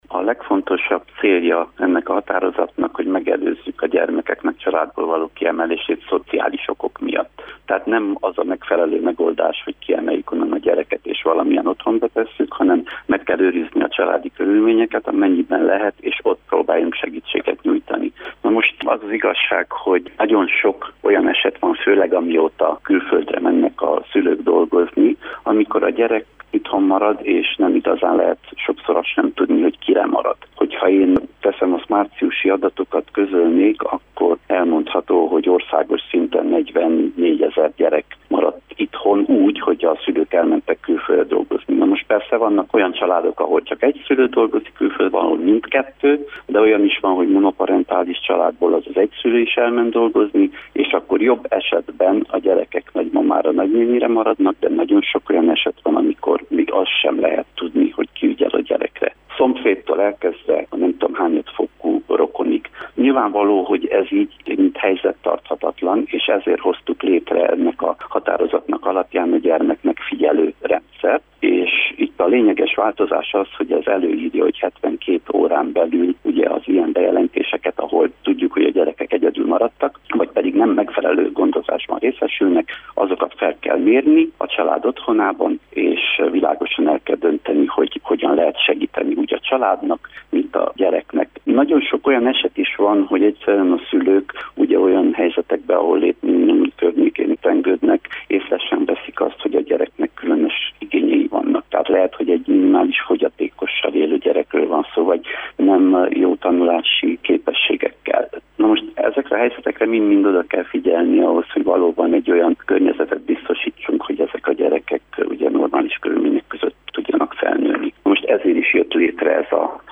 Országos „Gyermekmegfigyelő” rendszert hozott létre a kormány, amelynek célja az, hogy az elhanyagolt gyermekek helyzetét azonnali hatállyal felmérje és később kövesse is a sorsukat. Derzsi Ákos munkaügyi államtitkárt kérdeztük.